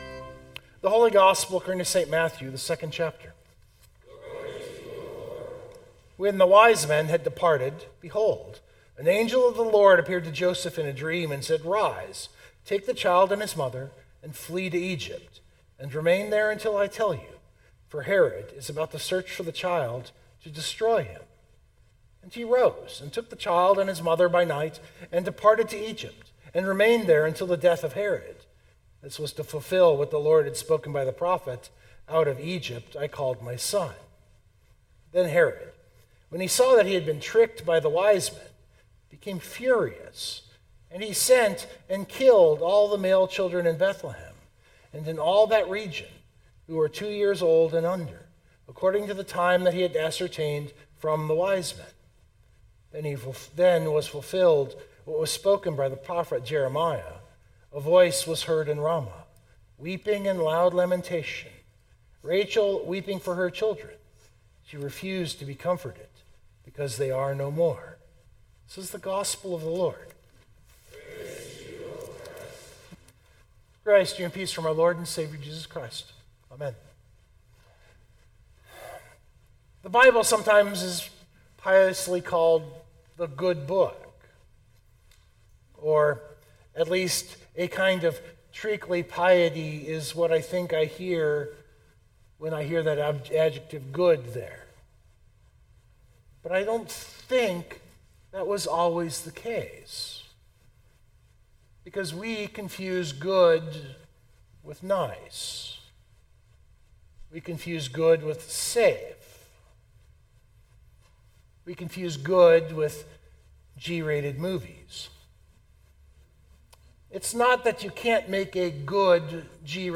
122825 sermon Download Biblical Text: Matthew 2:13-18 The day on the church calendar is called Holy Innocents.